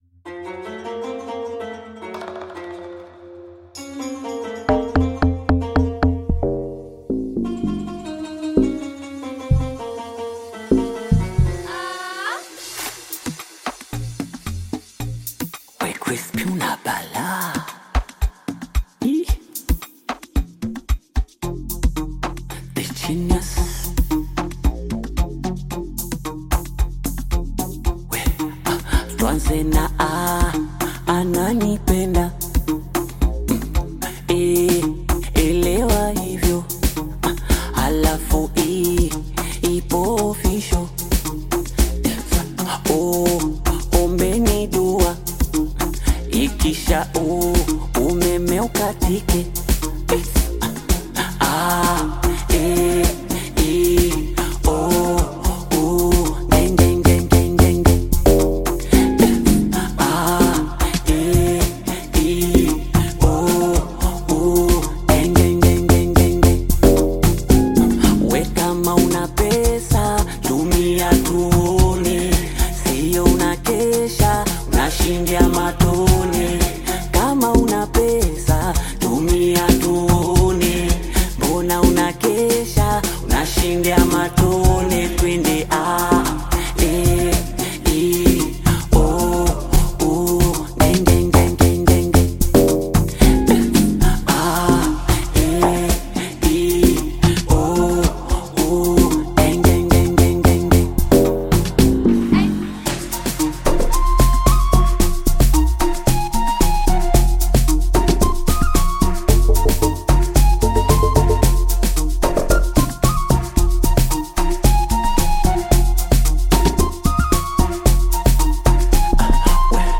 Bongo Flava music track
Tanzanian Bongo Flava artist, singer, and songwriter
Bongo Flava You may also like